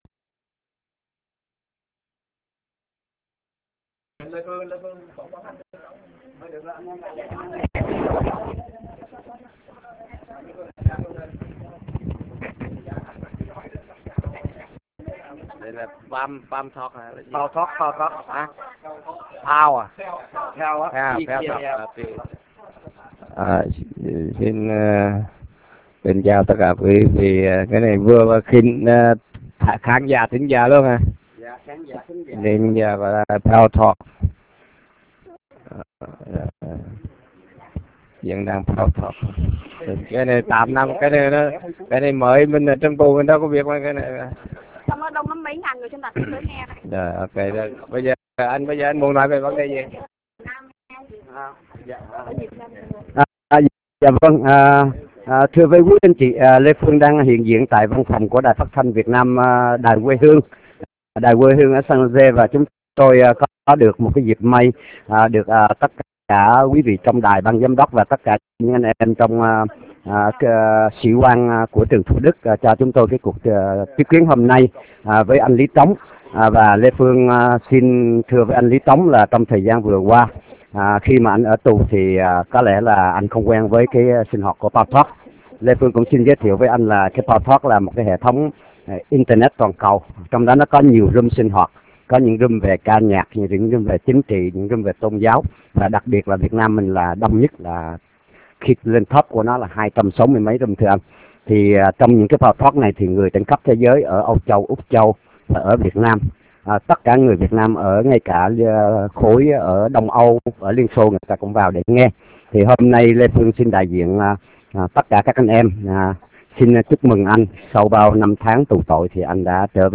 L� TỐNG TỪ TH�I LAN VỀ ĐẾN SAN JOS� BUỔI CHIỀU L� TỐNG N�I CHUYỆN TR�N PALTALK MỜI QU� VỊ LẮNG NGHE